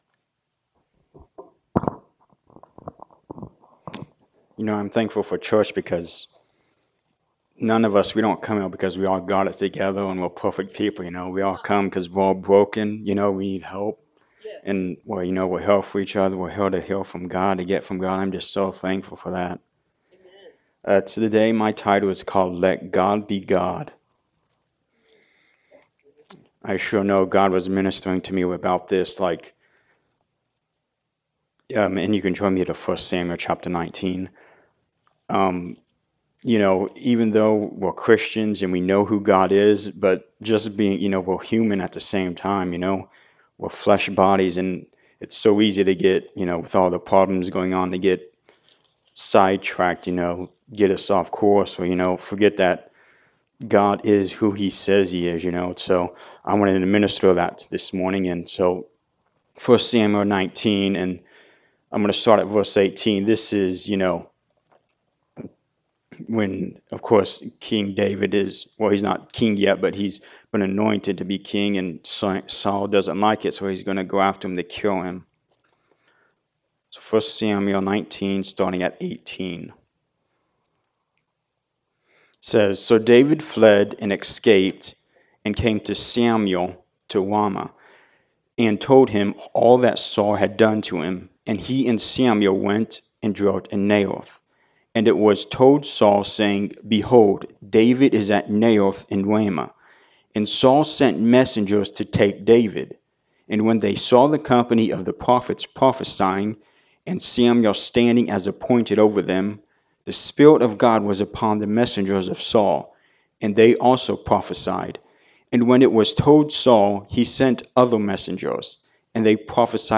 Hardships Service Type: Sunday Morning Service Let God Be God.